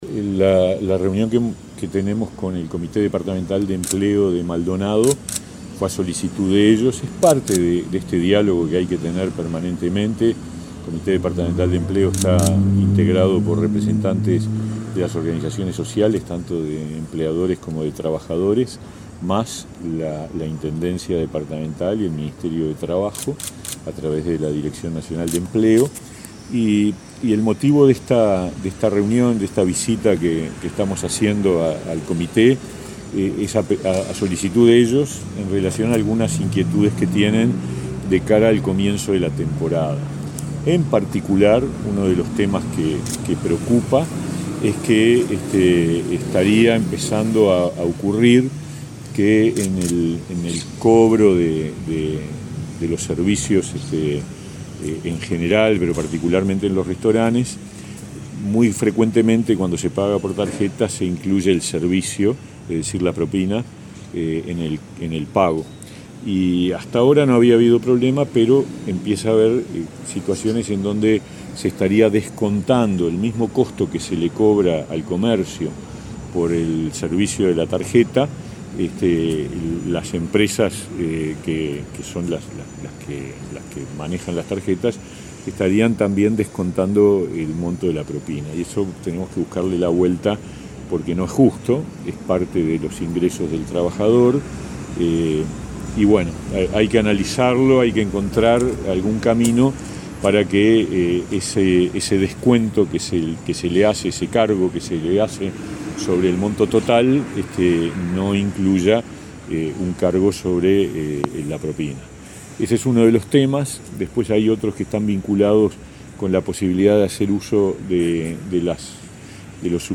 Entrevista al ministro de Trabajo, Pablo Mieres
Entrevista al ministro de Trabajo, Pablo Mieres 10/11/2022 Compartir Facebook X Copiar enlace WhatsApp LinkedIn El ministro de Trabajo, Pablo Mieres, mantuvo, en la sede de la Liga de Fomento de Punta del Este, una reunión con integrantes del Comité Departamental de Empleo de Maldonado, en la que se trataron diferentes temas vinculados a las perspectivas para la próxima temporada estival. Antes dialogó con Comunicación Presidencial.